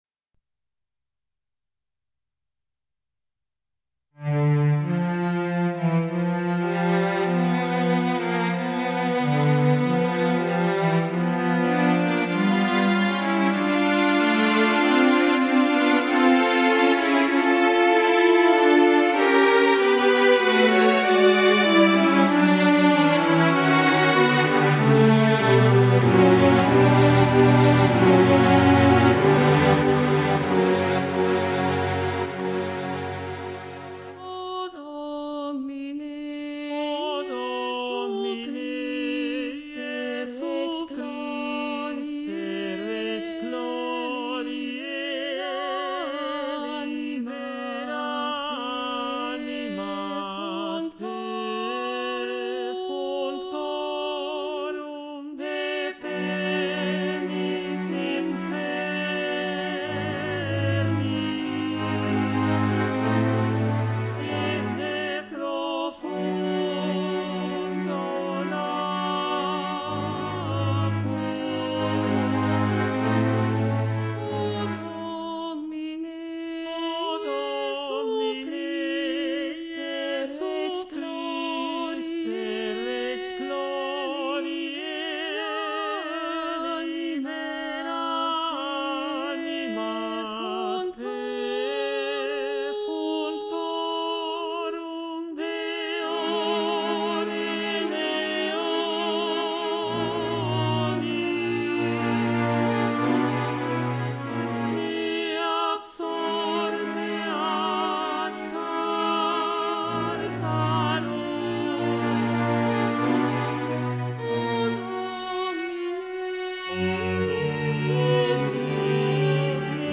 Soprano
Mp3 Profesora
2.-Ofertorio-SOPRANO-VOZ-1.mp3